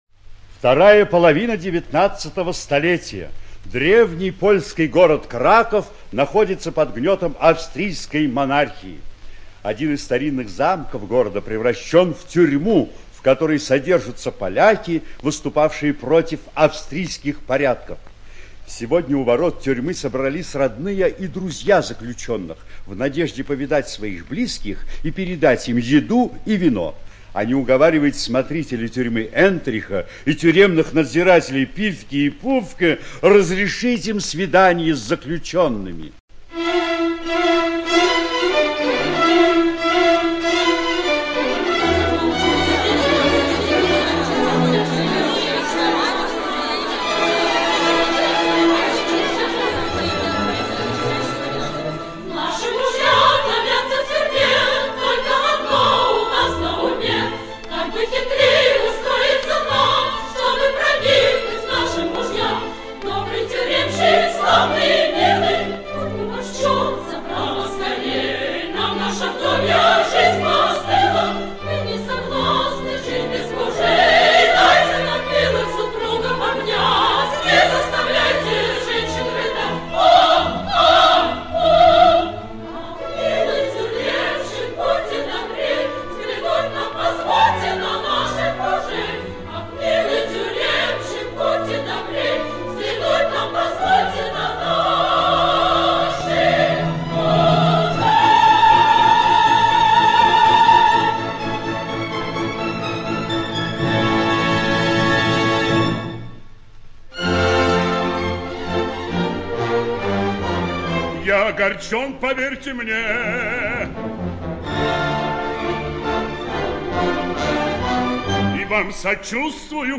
ЖанрМузыкальный радиоспектакль